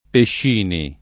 [ pešš & ni ]